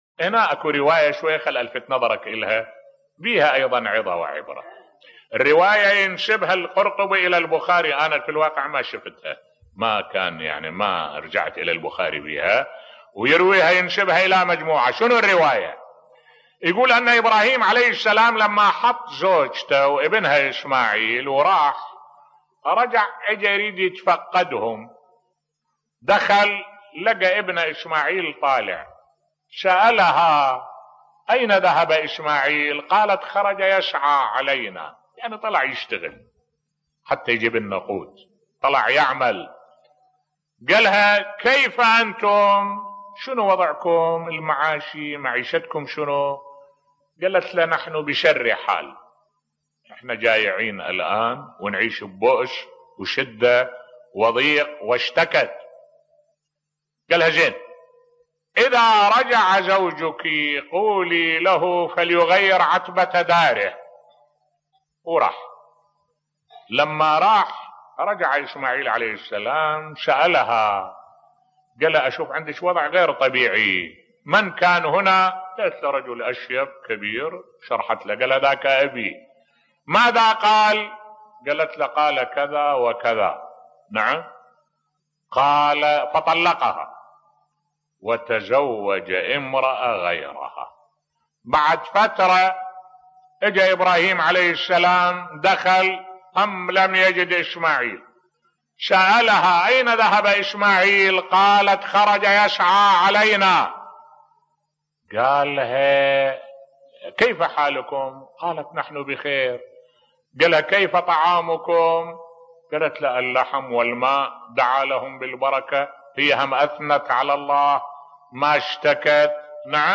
ملف صوتی الله لا يظلم أحد بصوت الشيخ الدكتور أحمد الوائلي